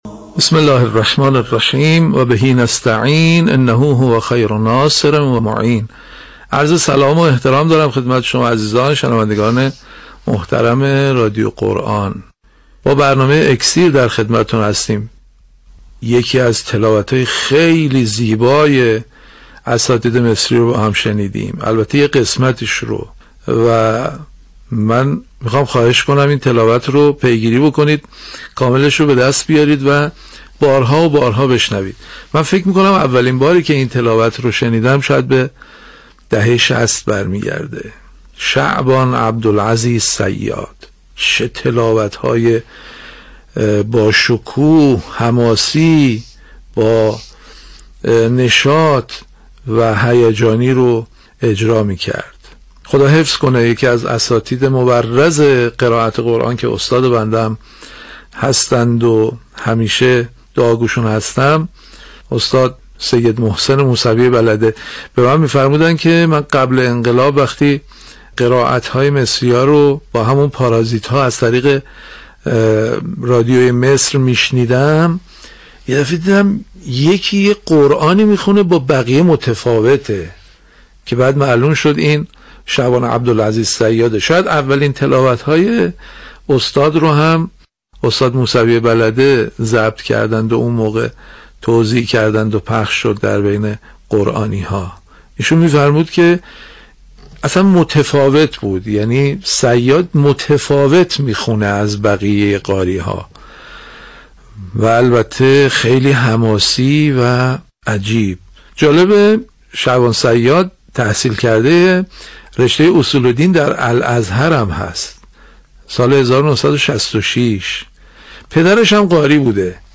صوت کارشناسی
یادآور می‌شود، این تحلیل در برنامه «اکسیر» به تاریخ 4 مرداد ۱۳۹7 ساعت ۱۶:۳۰ تا ۱۸ از شبکه رادیویی قرآن پخش شد.